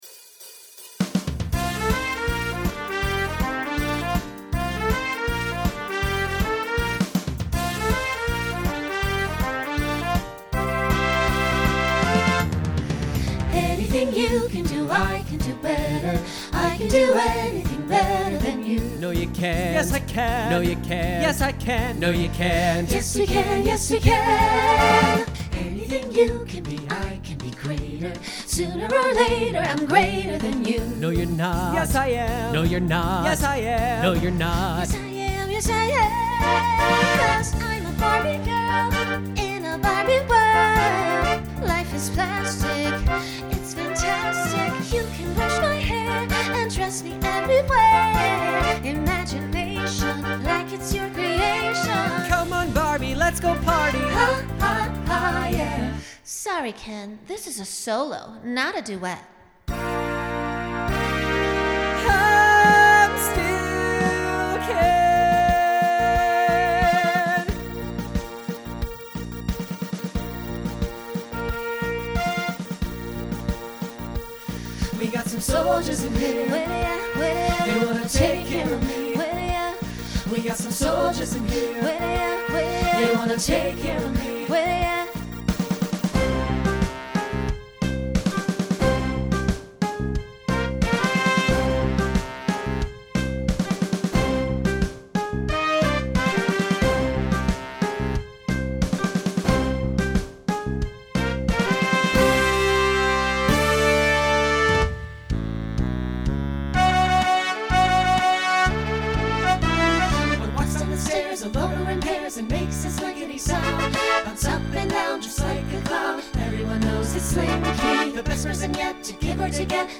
Broadway/Film , Pop/Dance , Rock
Voicing Mixed